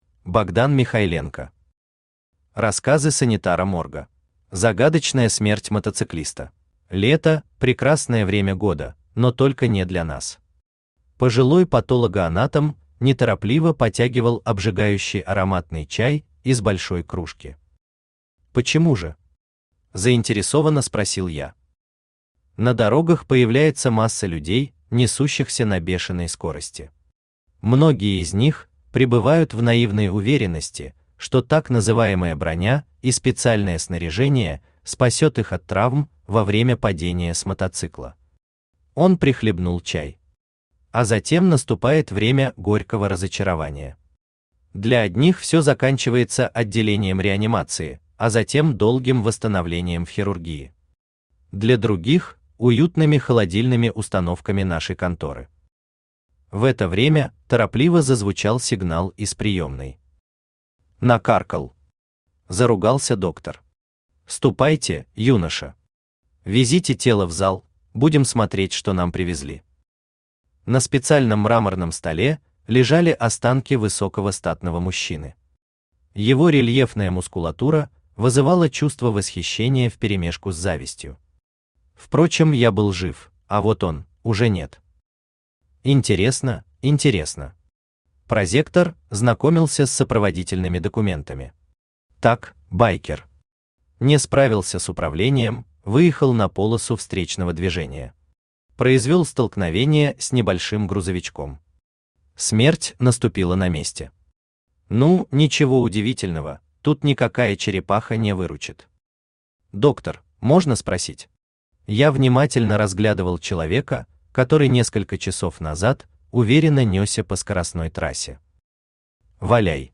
Аудиокнига Рассказы санитара морга | Библиотека аудиокниг
Aудиокнига Рассказы санитара морга Автор Богдан Васильевич Михайленко Читает аудиокнигу Авточтец ЛитРес.